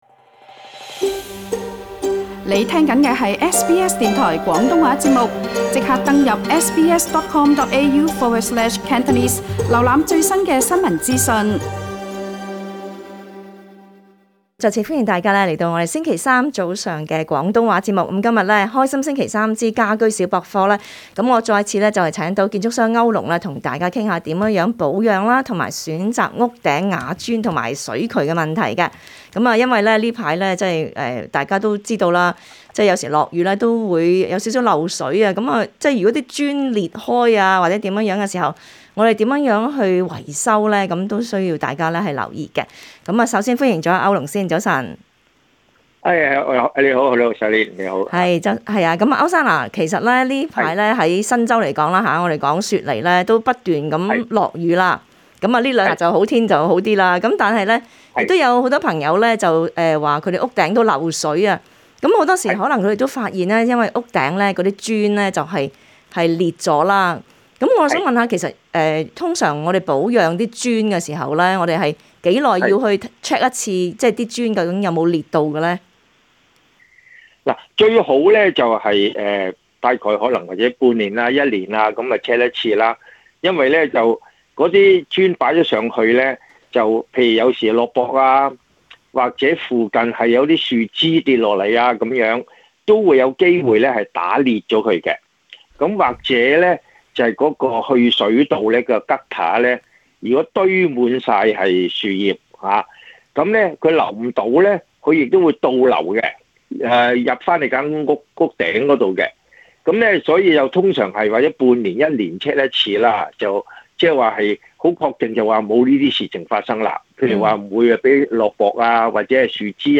SBS广东话播客
他们亦会解答听众的问题。